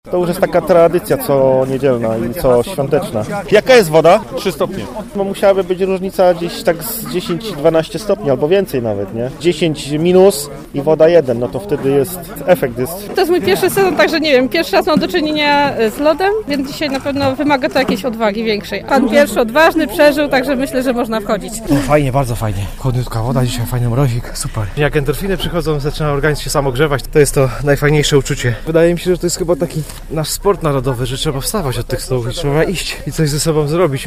Ponad 20 osób weszło przed południem do jeziora Kierskiego.